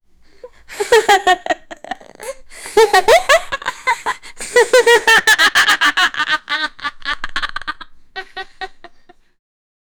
menina a rir feliz
menina-a-rir-feliz-nb6m6ry6.wav